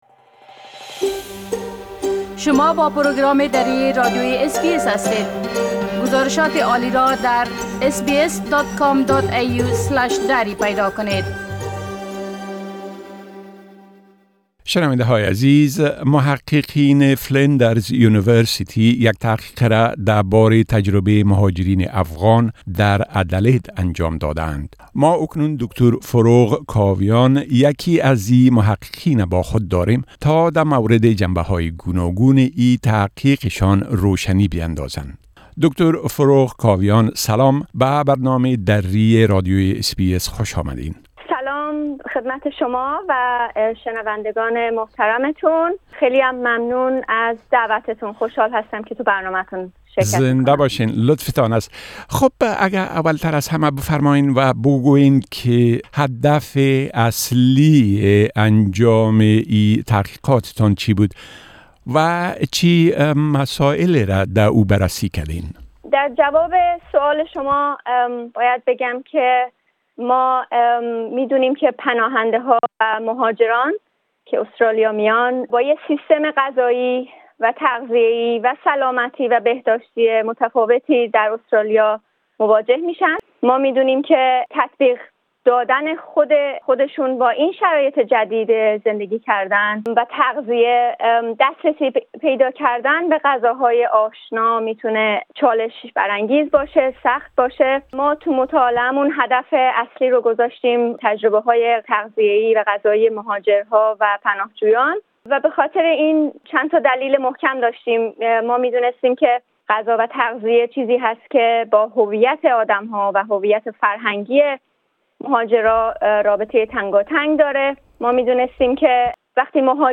گفت‌وگوی کامل اس‌بی‌اس دری